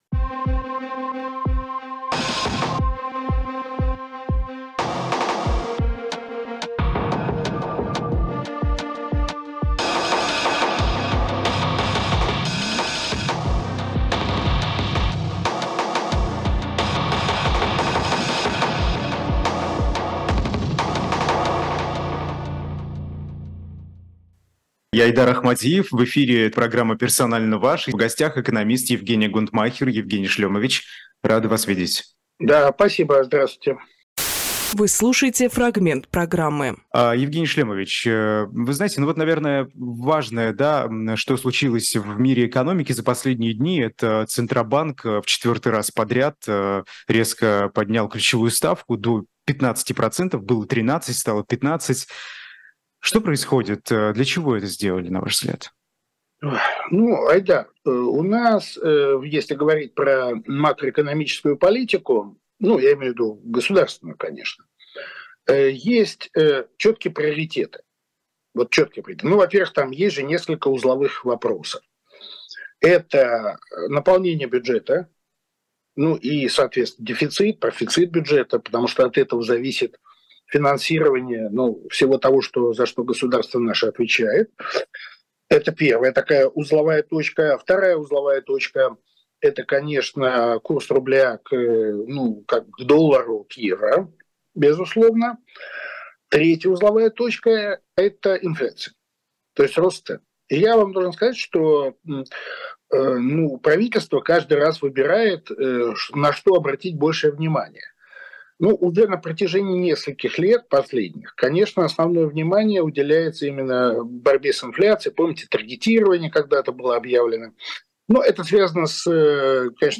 Фрагмент эфира от 31.10